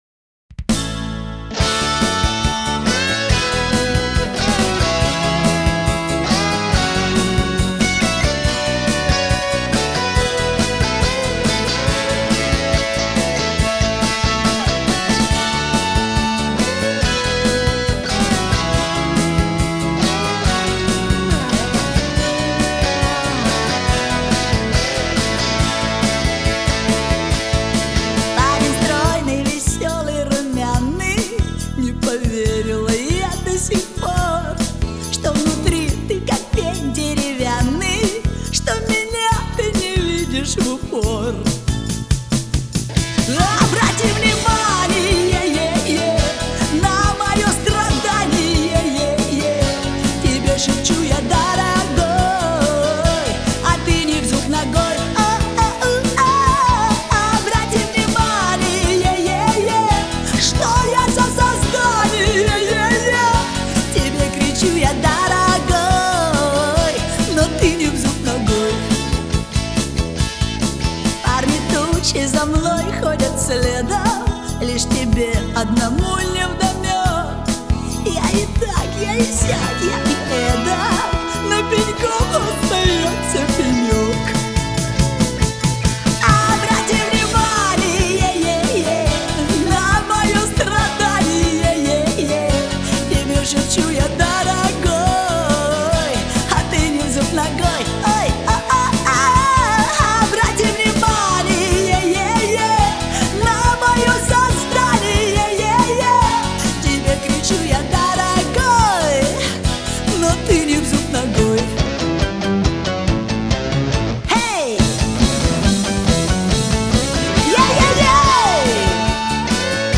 российская поп-певица